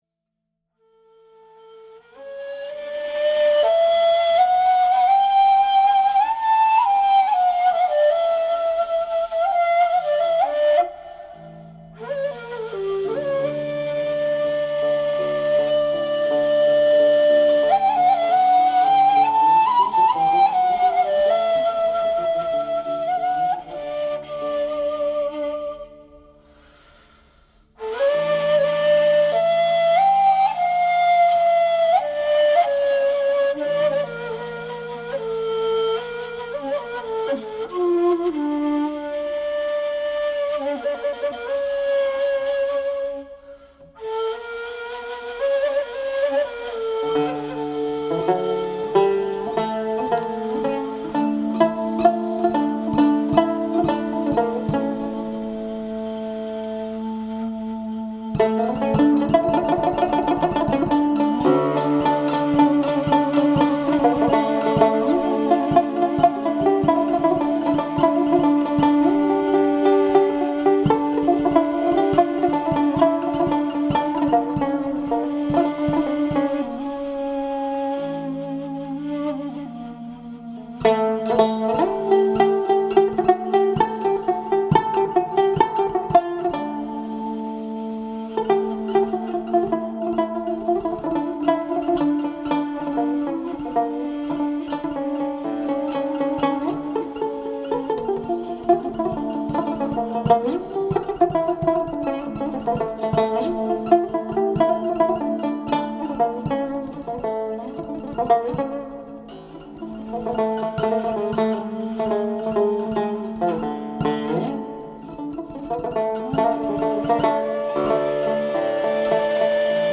Genre: Turkish & Ottoman Classical.
Recorded at Aria Studios, Istanbul
tanbur
ney